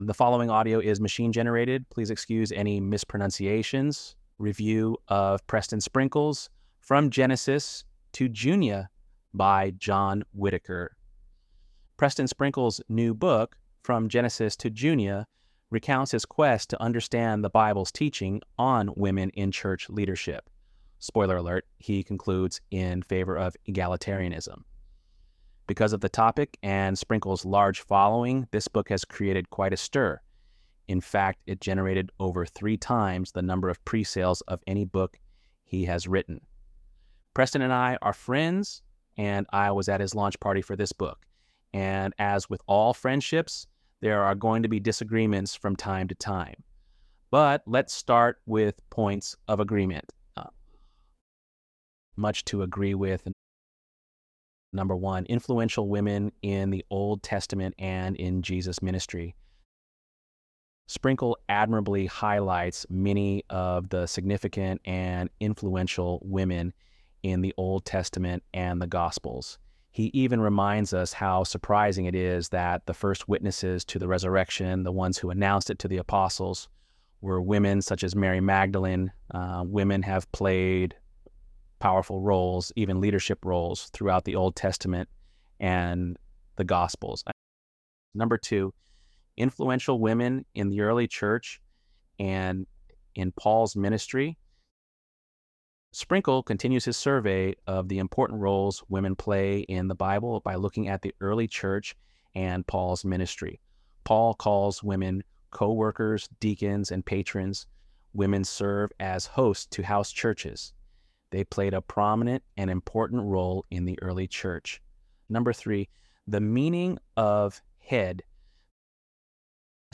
ElevenLabs_Untitled_project-49.mp3